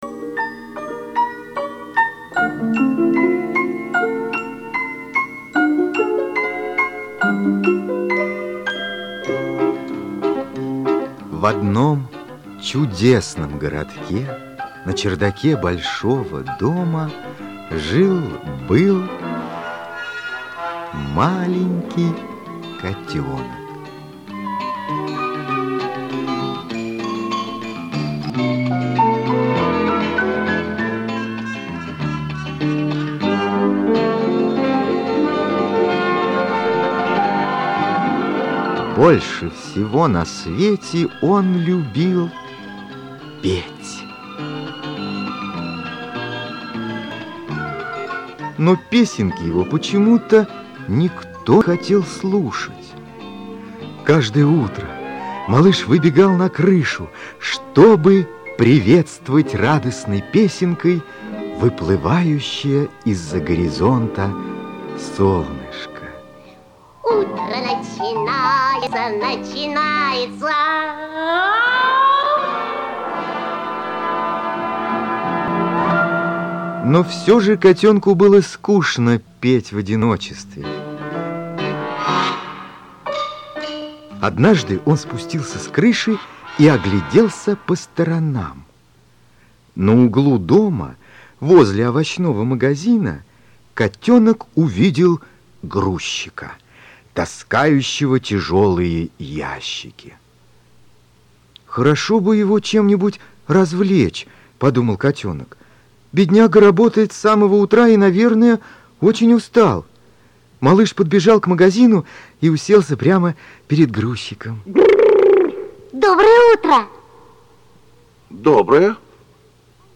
Чучело-мяучело - аудиосказка М.Д. Яснова